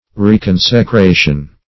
Reconsecration \Re*con`se*cra"tion\, n. Renewed consecration.